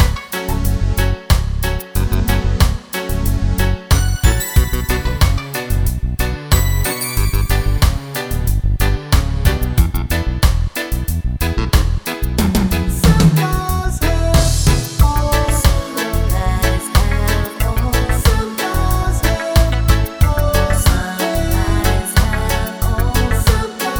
no sax Reggae 3:39 Buy £1.50